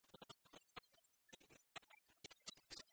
Mémoires et Patrimoines vivants - RaddO est une base de données d'archives iconographiques et sonores.
enfantine : comptine
Pièce musicale inédite